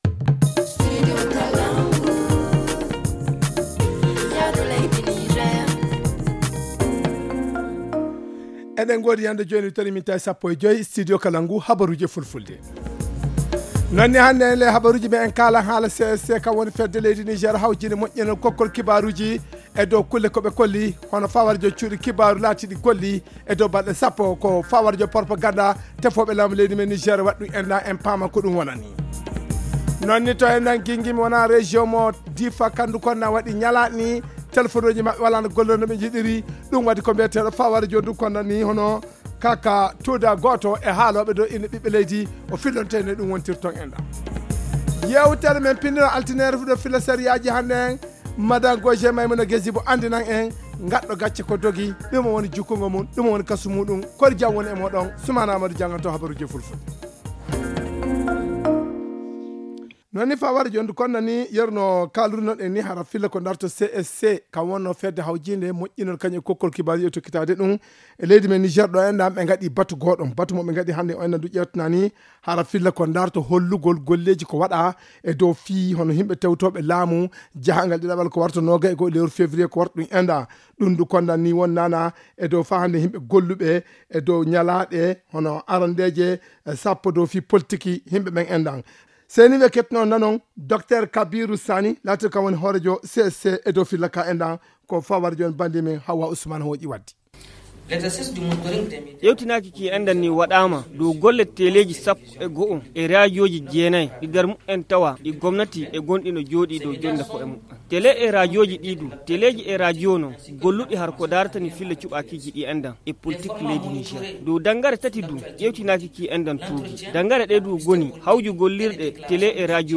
Le journal du 15 février 2021 - Studio Kalangou - Au rythme du Niger